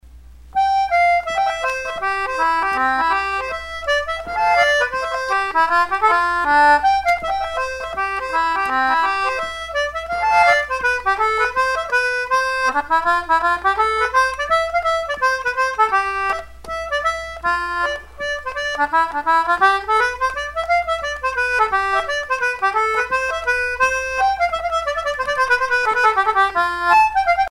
danse : hornpipe
circonstance : maritimes
Pièce musicale éditée